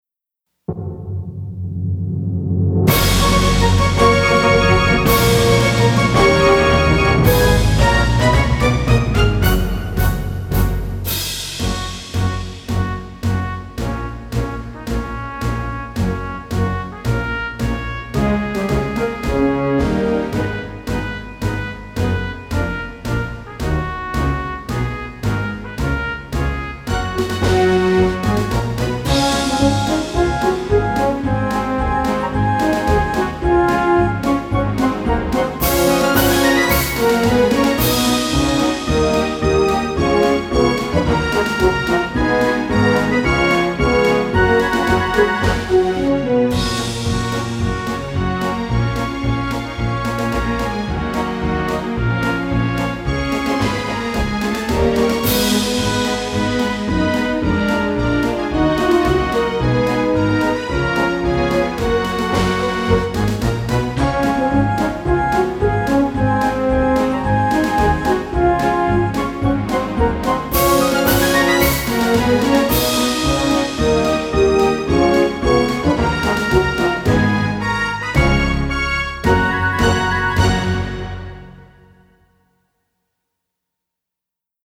ROTC찬가_MR.mp3